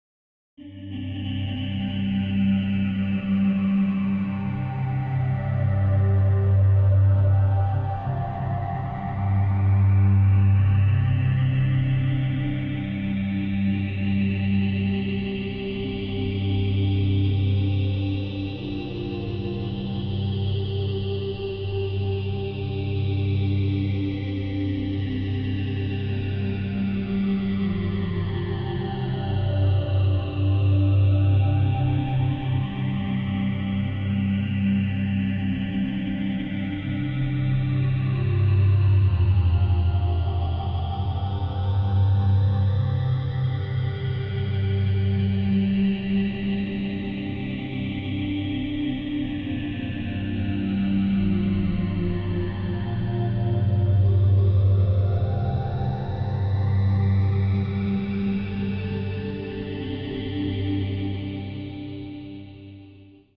All such variations created different unique music.